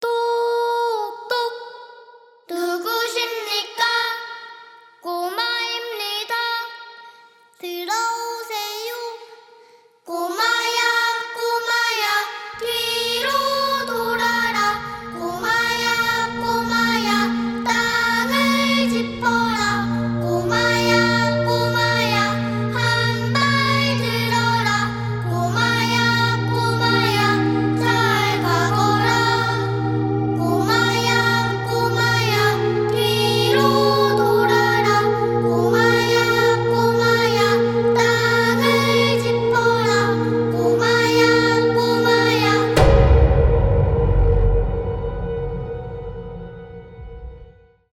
детский голос , ost